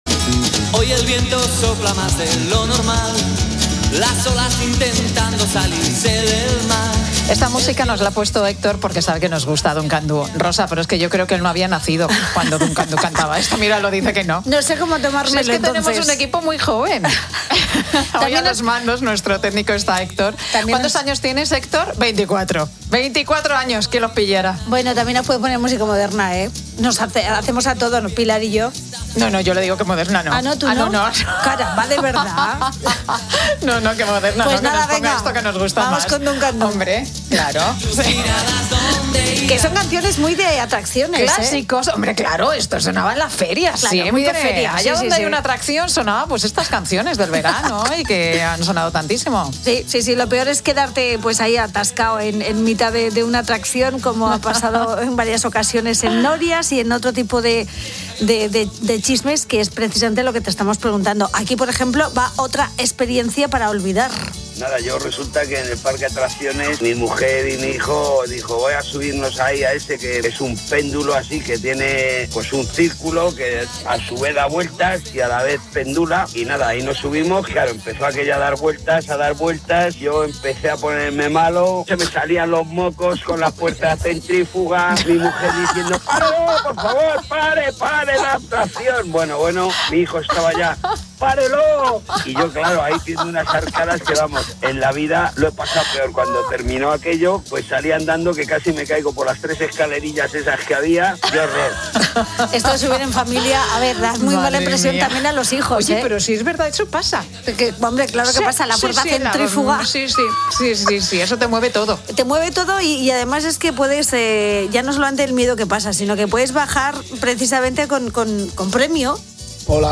Los oyentes del programa 'La Tarde' relatan sus anécdotas más insólitas y accidentadas en ferias y parques de atracciones, algunas son realmente surrealistas